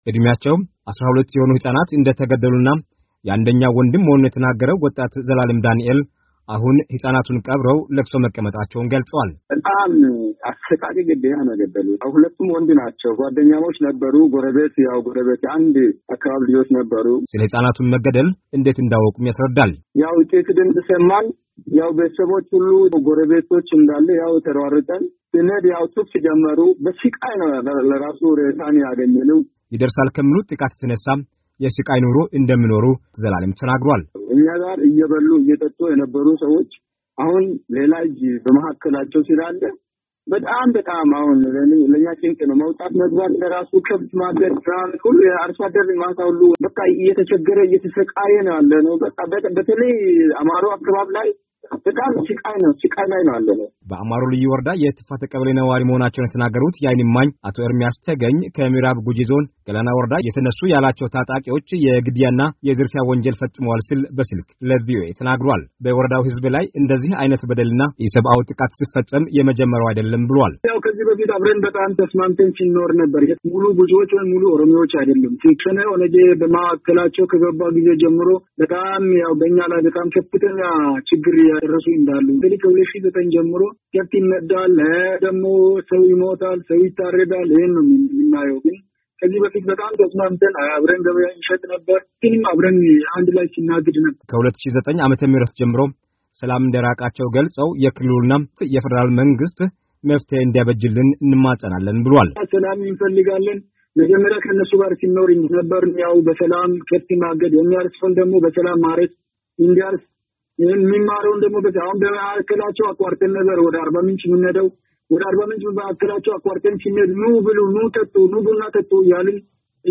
ታጣቂዎች በኦሮሚያ ክልል ምዕራብ ጉጂ ዞን እንደሚንቀሳቀሱ በስልክ ለአሜሪካ ድምፅ የገለጹት የወረዳው ነዋሪዎች እና ቤተሰቦቻቸው ከብቶቻቸው መዘረፉንም ተናግረዋል።